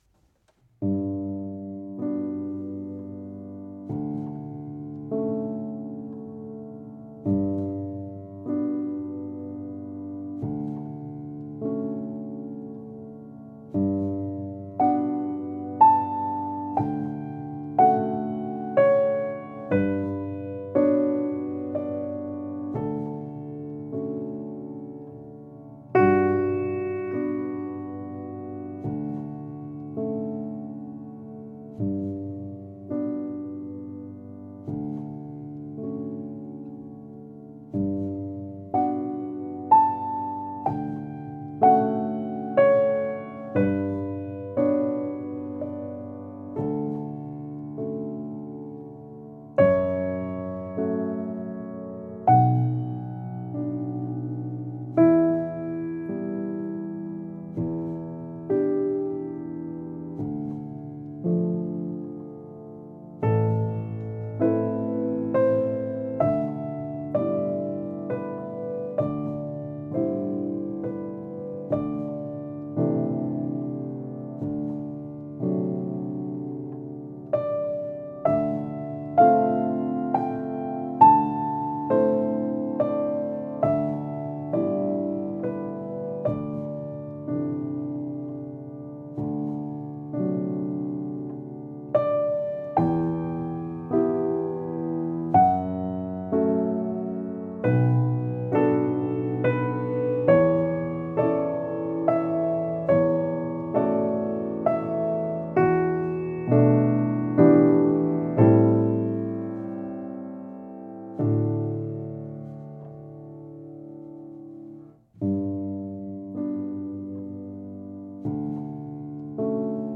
Klavier: Bösendorfer 180 Bj. 1919